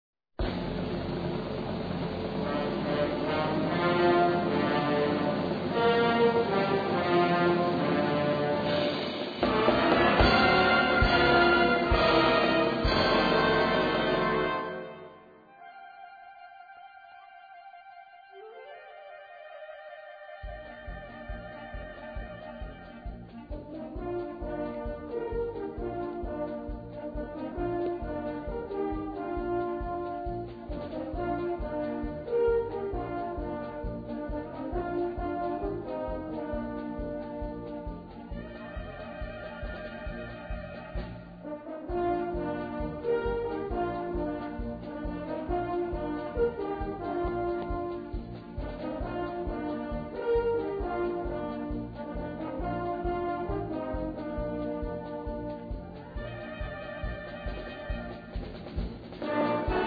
Gattung: Potpourri
Besetzung: Blasorchester